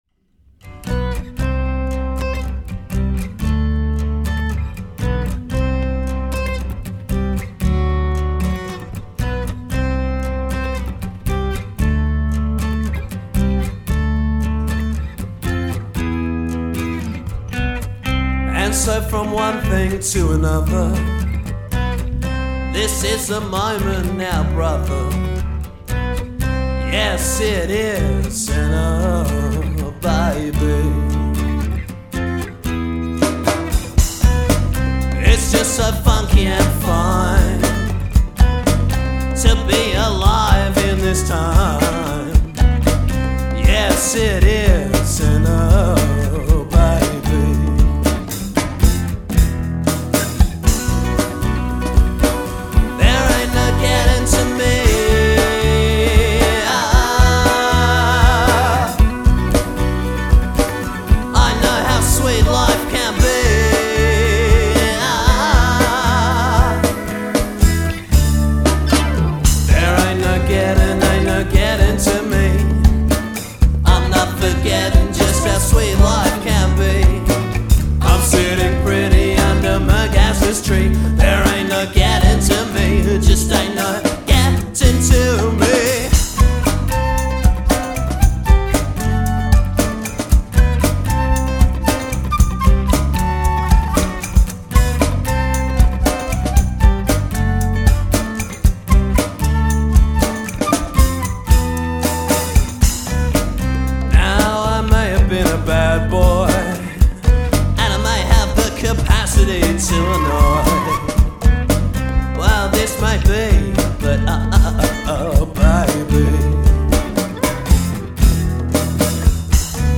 percussion
guitar